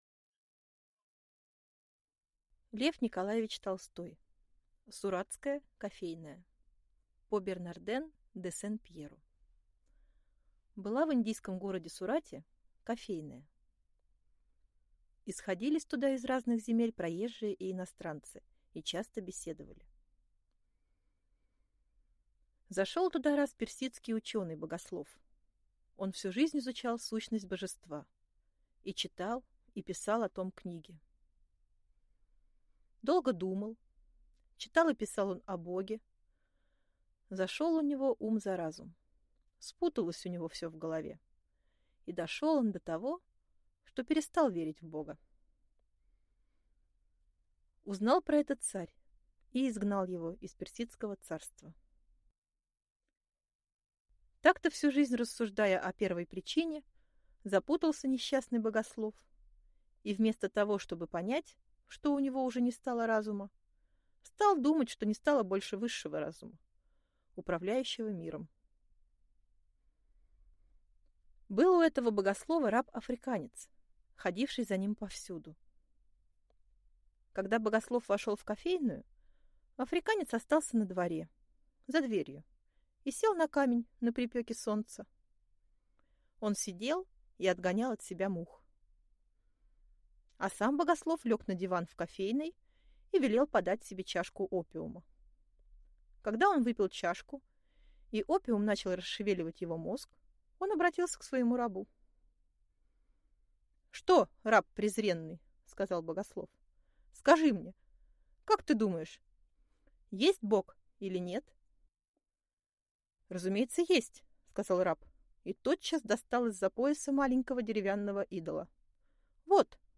Аудиокнига Суратская кофейная | Библиотека аудиокниг